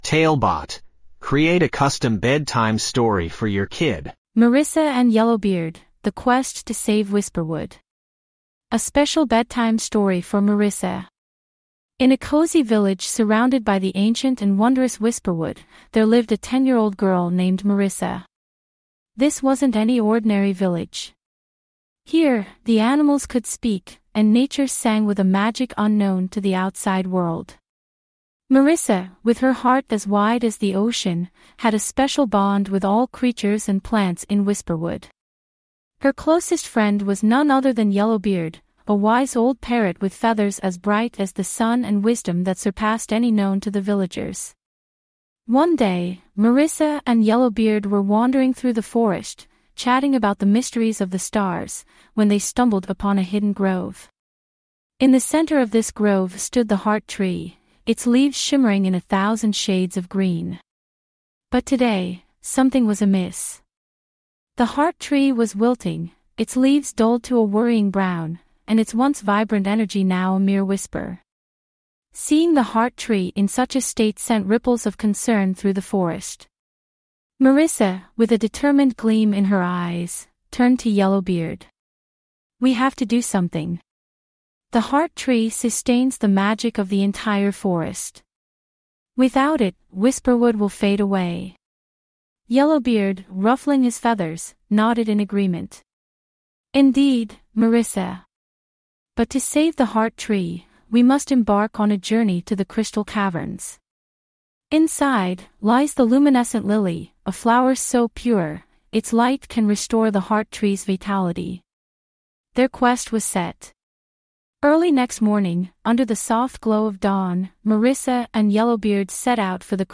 5 minute bedtime stories.
TaleBot AI Storyteller